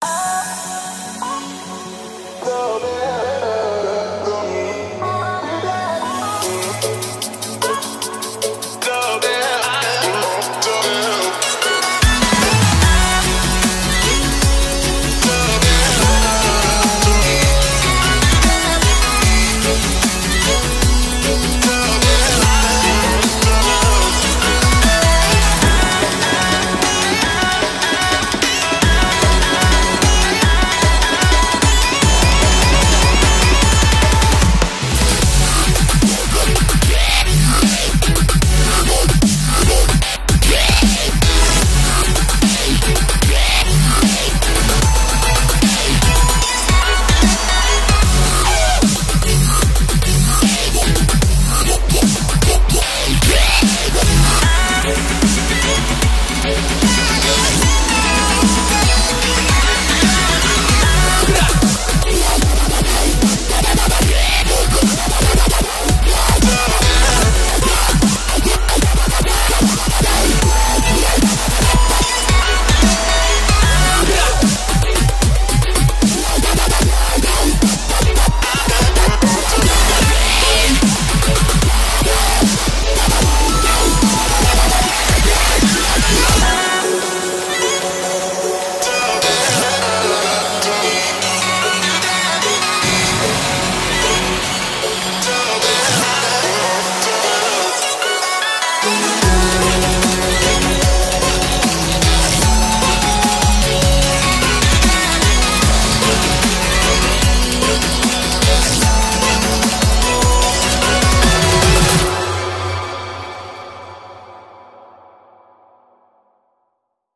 BPM150
Comments[RIDDIM DUBSTEP]
Wub wub wub.